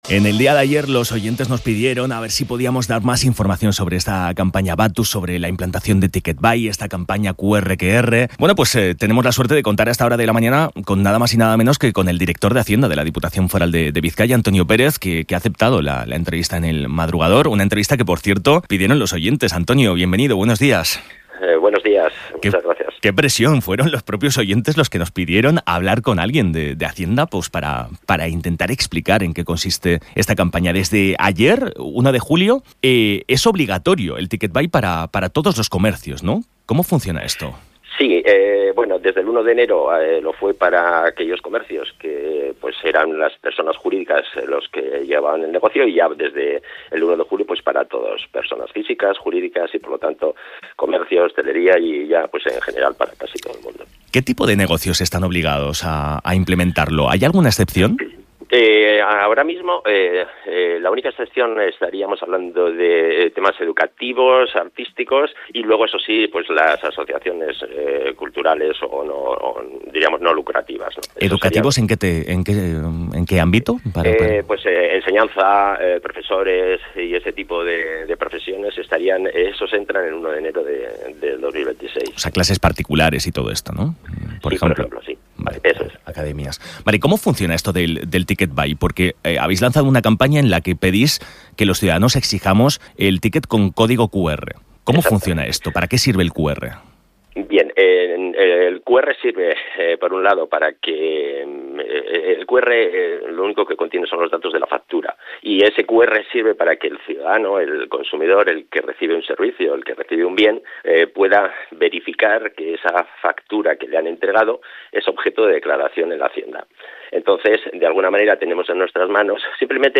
Hablamos con Antonio Pérez, director de Hacienda de Bizkaia sobre TicketBai